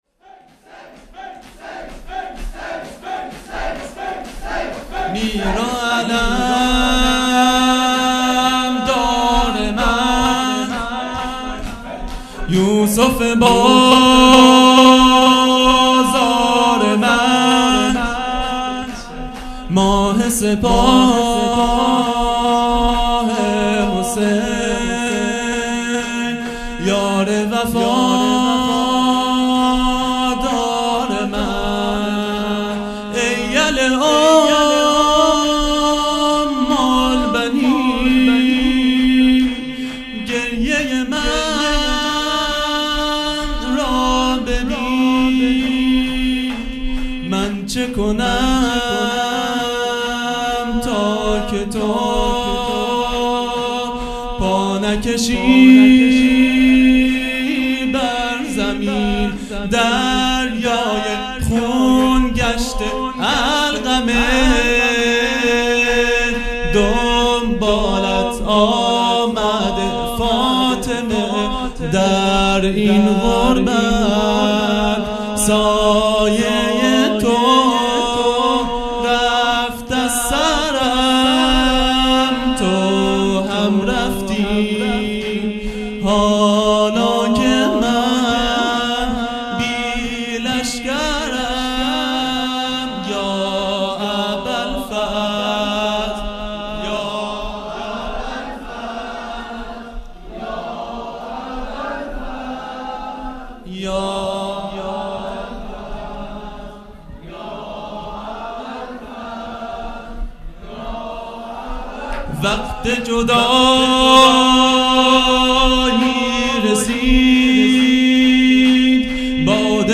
خیمه گاه - هیئت بچه های فاطمه (س) - شور | میر و علمدار من
دهه اول محرم الحرام ۱۴۴٢ | شب تاسوعا